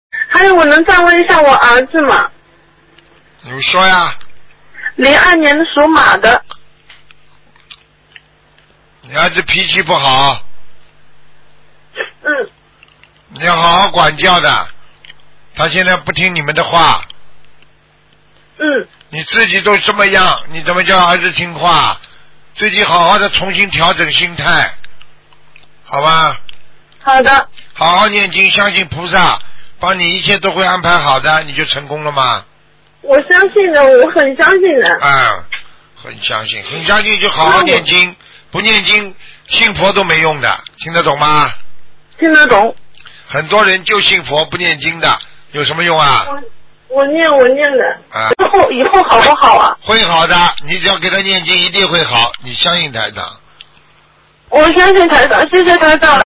目录：2016年剪辑电台节目录音_集锦